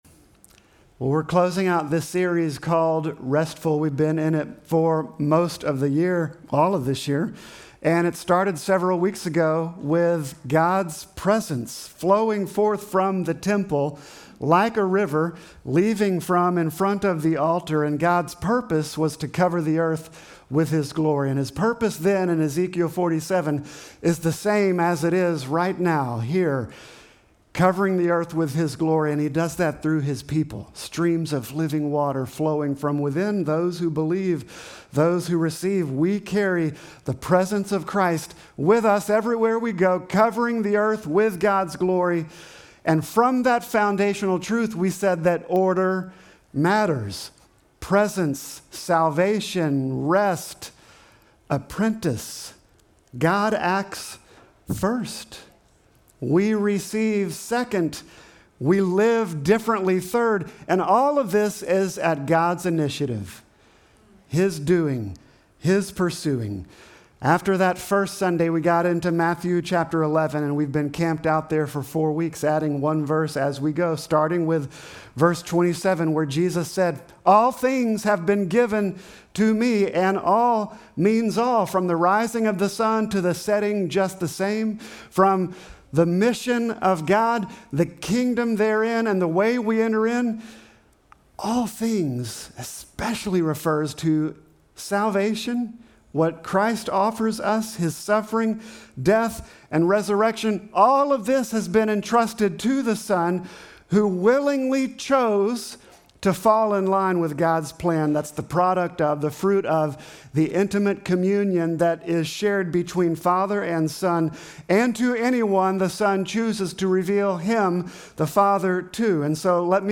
Sermon text: Matthew 11:27-30